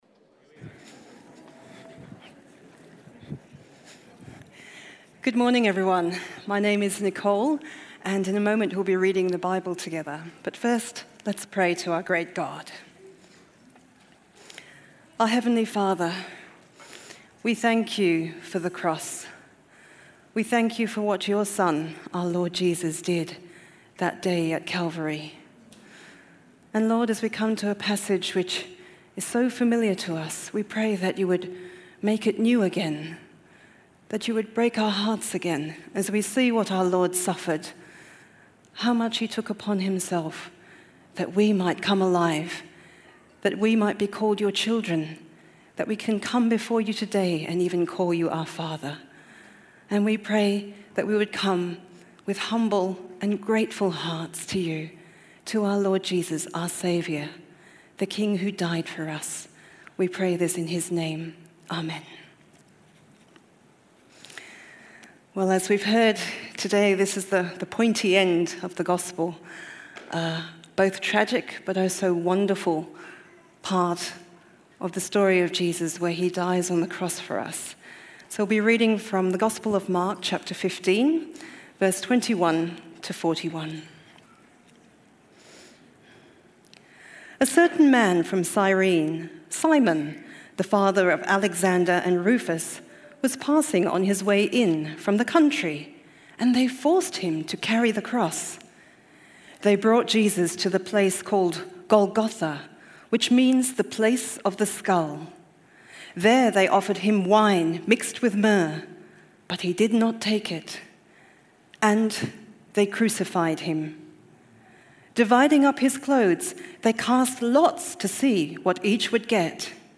Dying-King-Talk.mp3